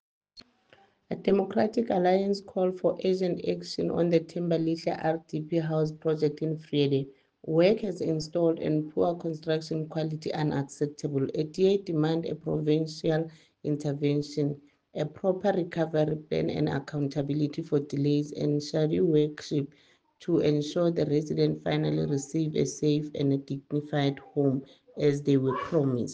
English soundbite by Cllr Ntombi Mokoena, Afrikaans soundbite by Cllr Doreen Wessels, and Sesotho soundbite by Cllr Diphapang Mofokeng.